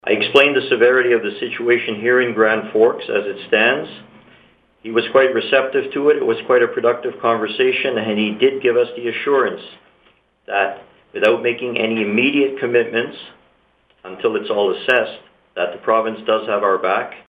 Grand Forks Mayor Frank Konrad says he’s in communication with Premier John Horgan.